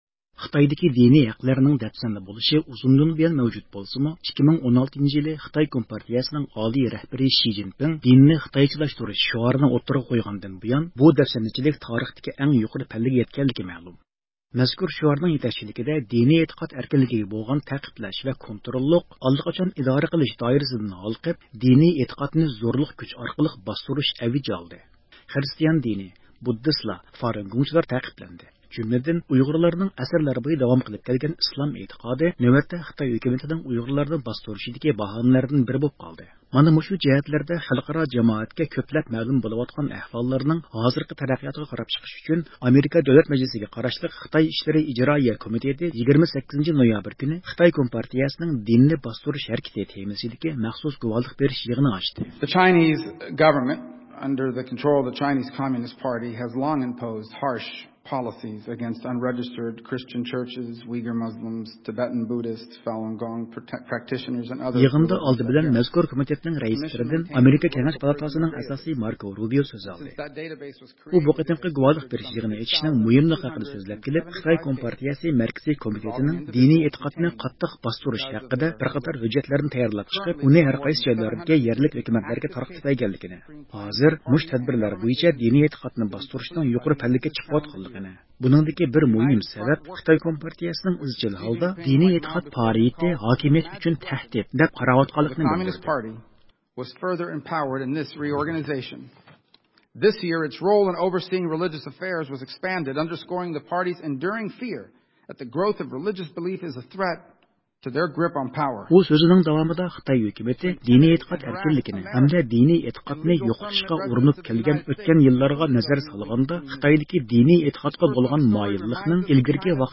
يىغىندا ئالدى بىلەن مەزكۇر كومىتېتنىڭ رەئىسلىرىدىن ئامېرىكا كېڭەش پالاتاسىنىڭ ئەزاسى ماركو رۇبيو سۆز ئالدى.